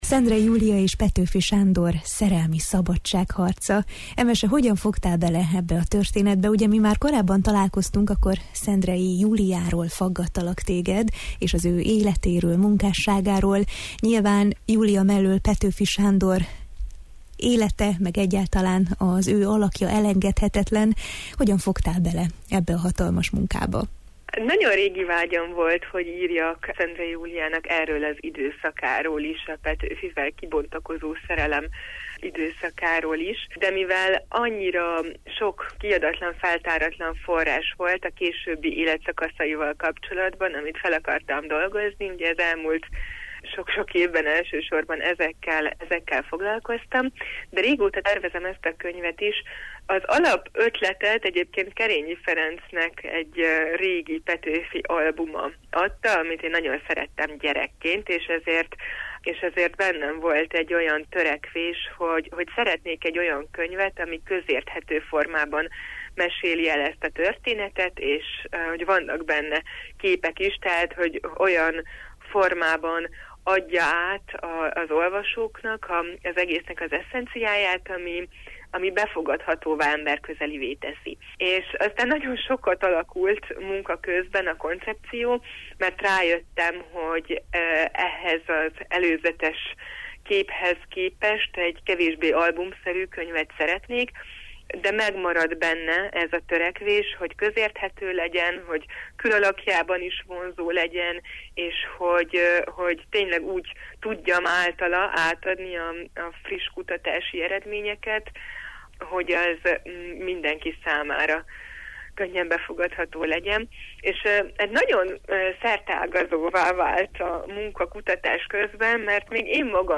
beszélgettünk a Jó reggelt, Erdély!-ben: